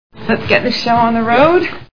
Iron Man Movie Sound Bites